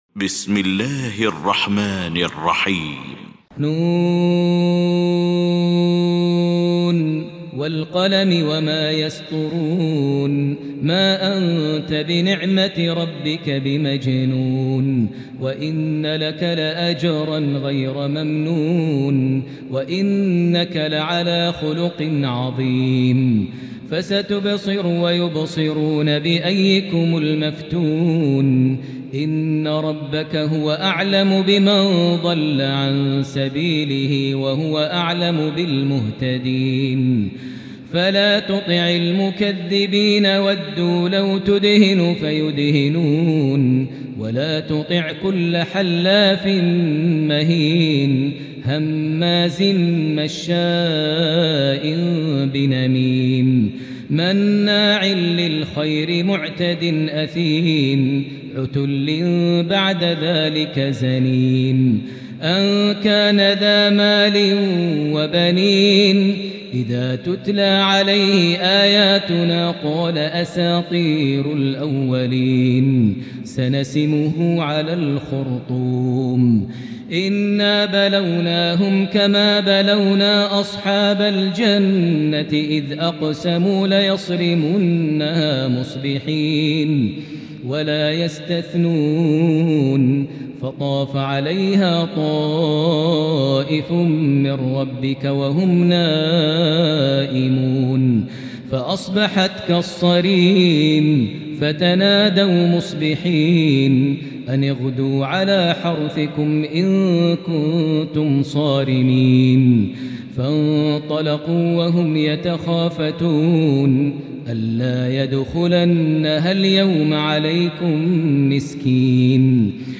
المكان: المسجد الحرام الشيخ: فضيلة الشيخ ماهر المعيقلي فضيلة الشيخ ماهر المعيقلي القلم